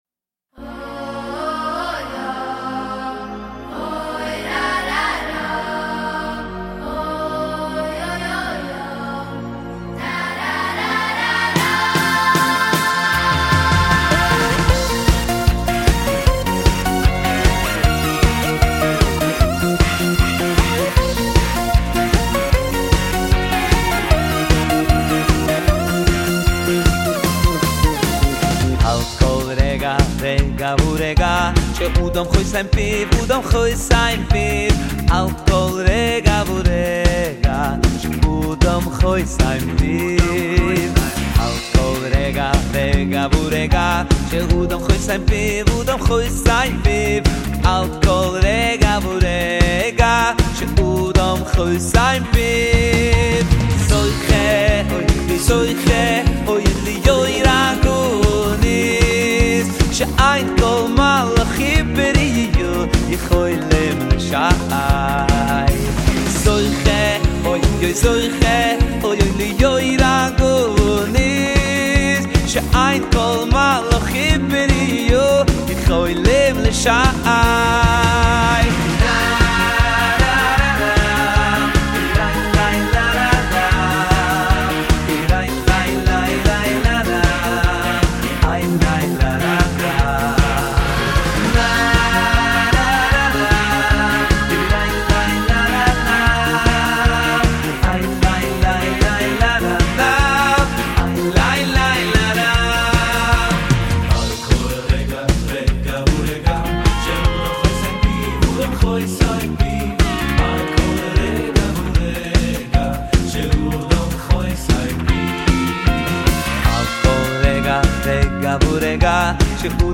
סינגל חדש